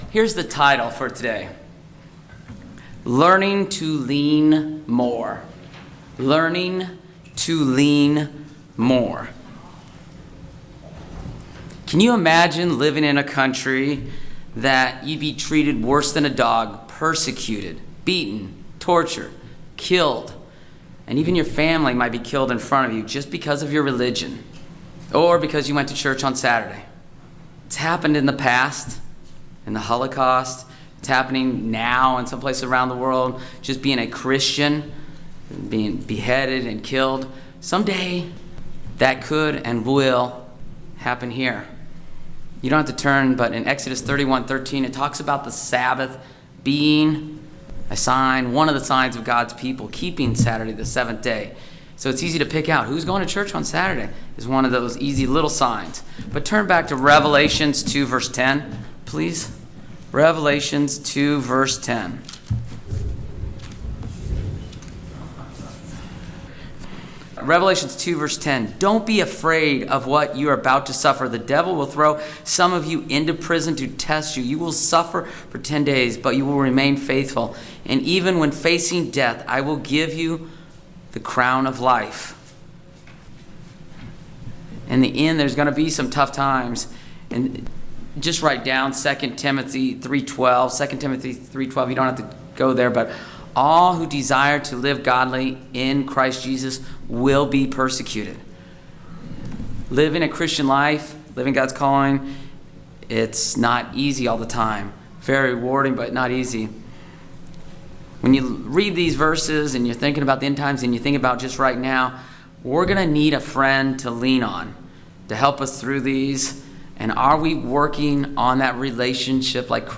The purpose of this sermon is to teach the importance of learning to lean and trust more on our best friend everyday.
Given in Ft. Wayne, IN